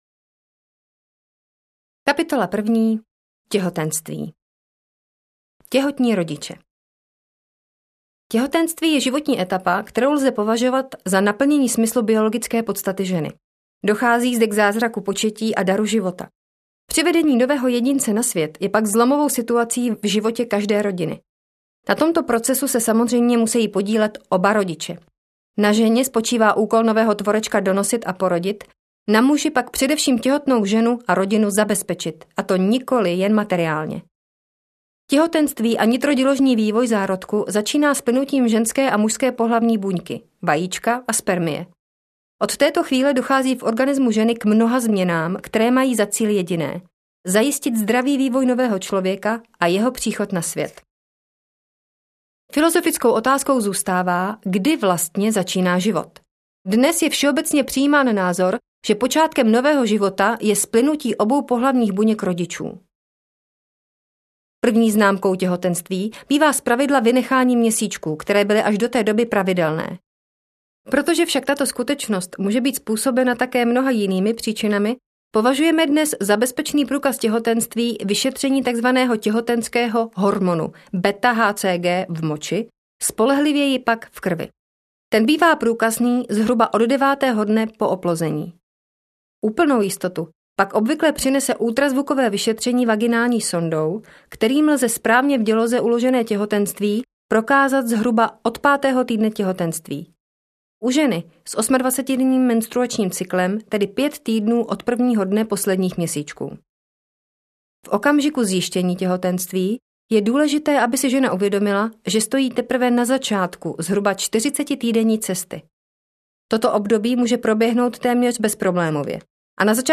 Ukázka z knihy
cekame-detatko-audiokniha